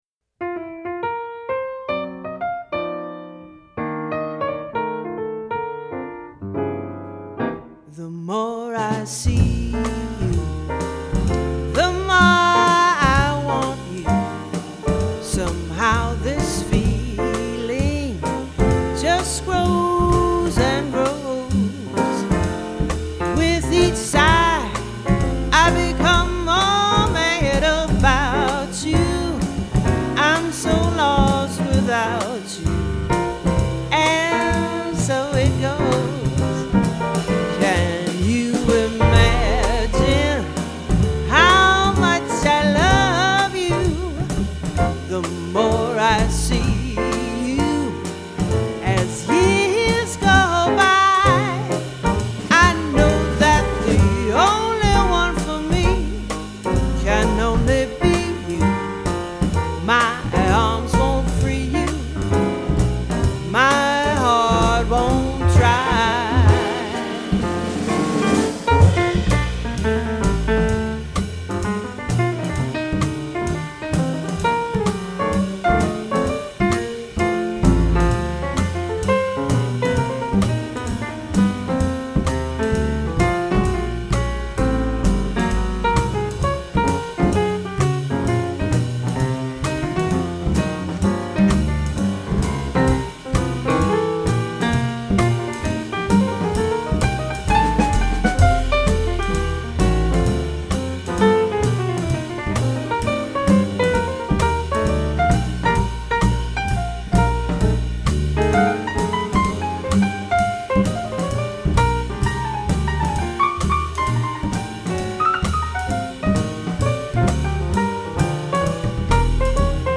hip, rip roaring live jazz
saxophone
jazz standards